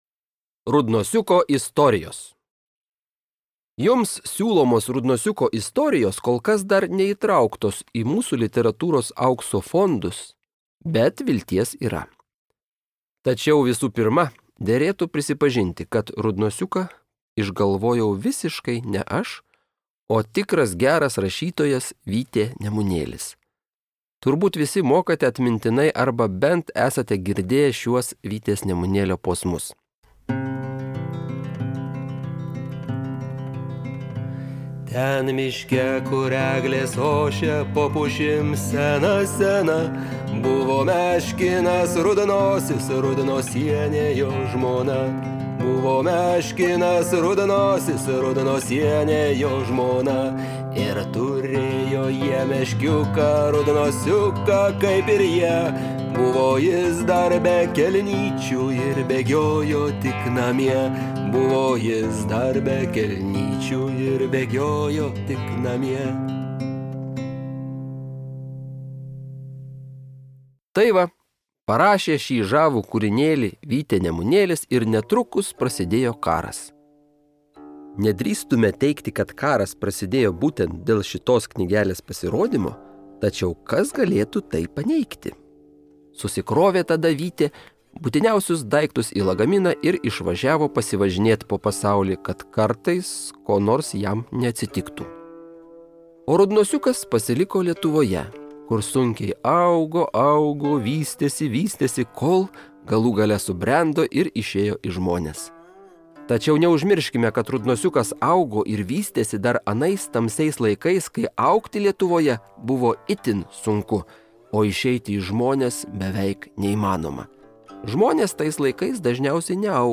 Pohadka_na_poslech_doma_01.mp3